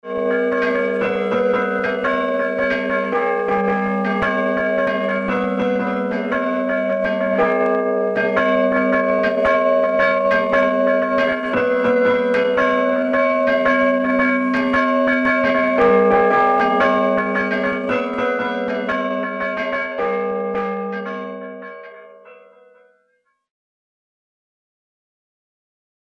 ICI, le carillon de Saorge
Campane.mp3